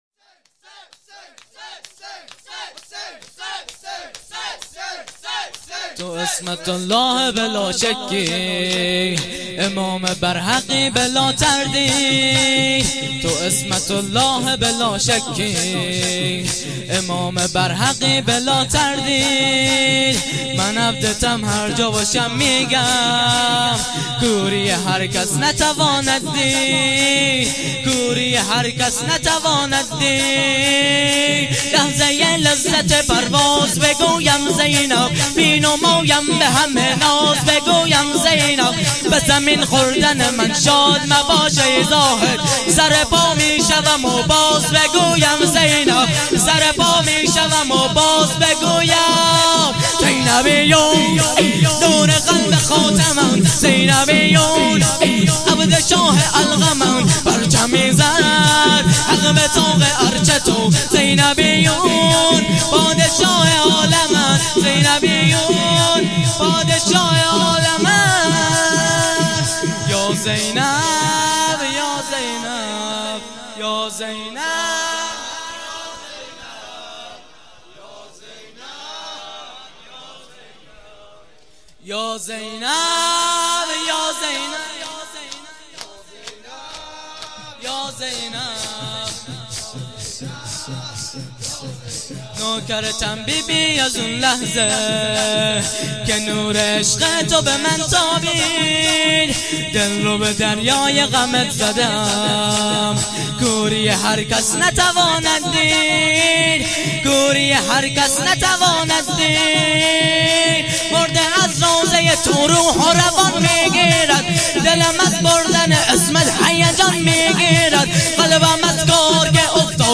شهادت امام موسی کاظم (ع) 97.1.23